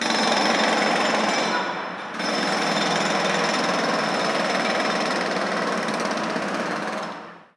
Título Martillo neumático (obra) Formateatu: audio/mpeg Fitxategiaren tamaina 119.04 KB Iraupena: 7 seconds: Me gusta Descriptores martillo neumático neumático Energía y su transformación Electricidad y electrónica.
Sonidos: Industria Sonidos: Ciudad